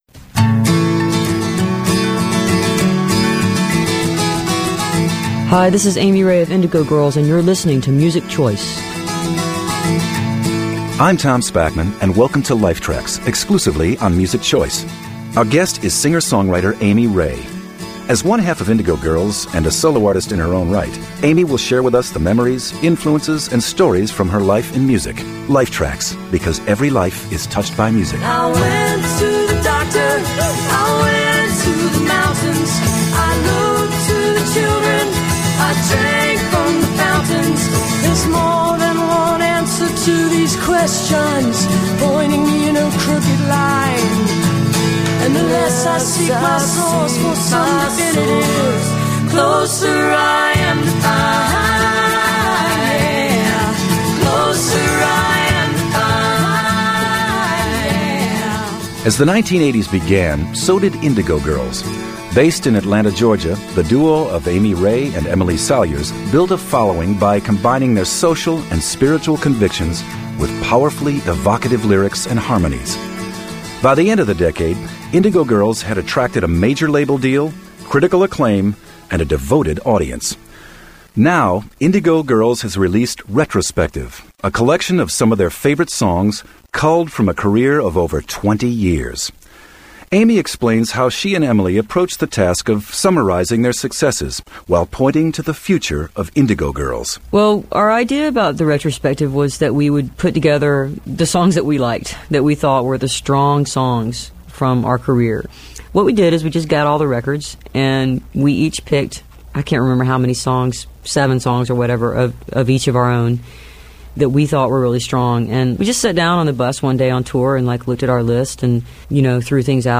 lifeblood: bootlegs: 2001-12-01: amy ray interview - music choice life tracks
01. interview - devotion (5:23)